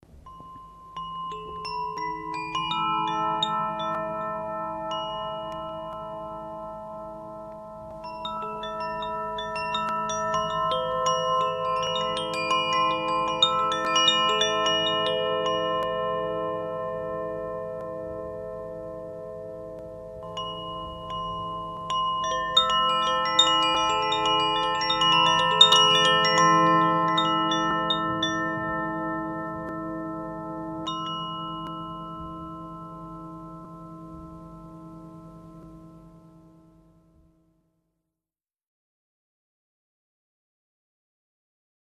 • Aria (Vzduch): A C E A B C E B
Každá zvonkohra má osm tónů a vyznačuje se magickým timbrem.
Jsou oblíbené pro svůj čistý, harmonický zvuk a schopnost vytvářet uklidňující zvukovou krajinu.
A moll